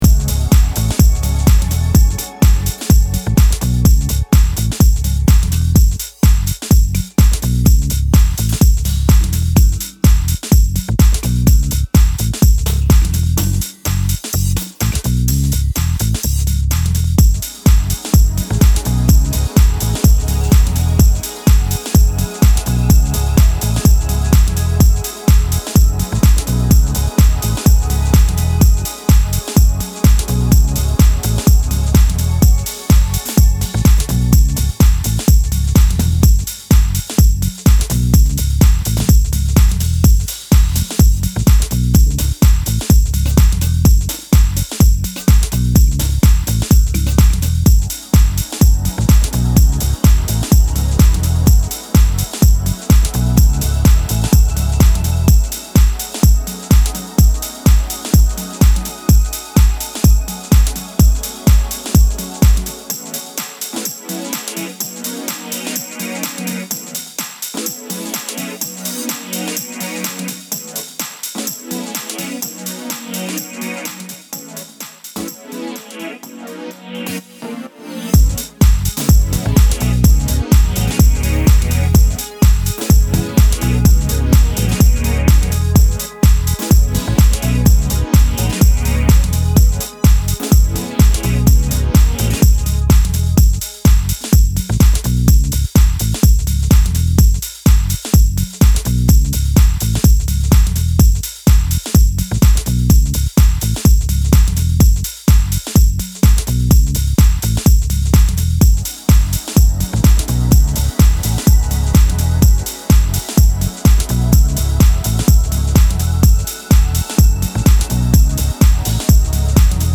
infectious grooves and funk-driven house